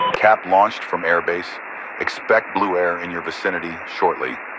Radio-pilotNewFriendlyAircraft4.ogg